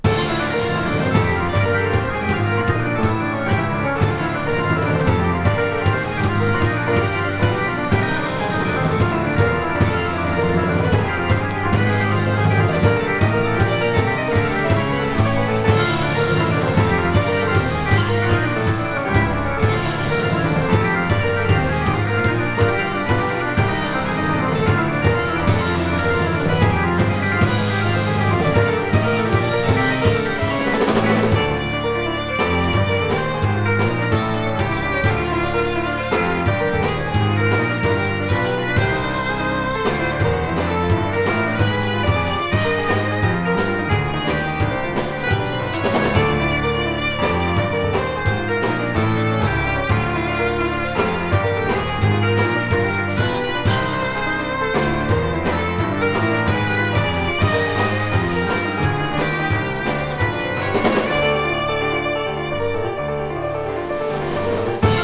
スイスのシンフォニック・ロック、プログレッシブ・ロックバンド
キーボード×2、ドラムの編成がユニーク！